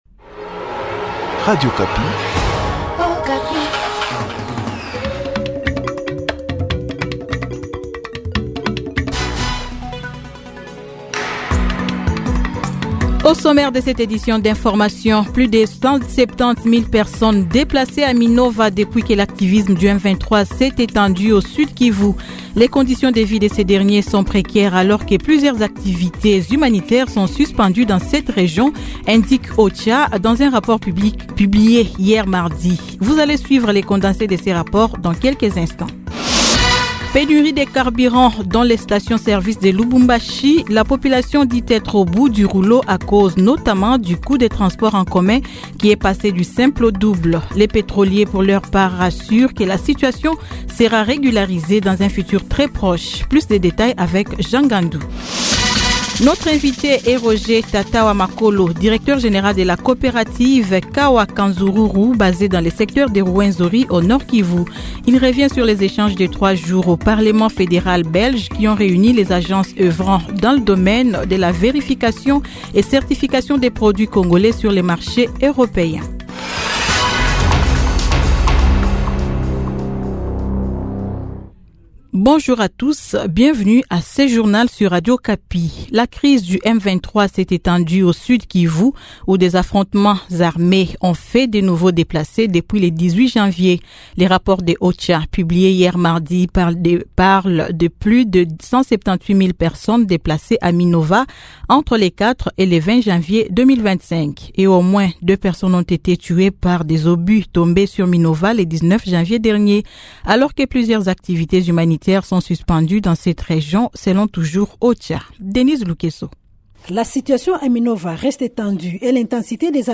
Journal Midi
Journal Midi de mercredi 22 Janvier 2025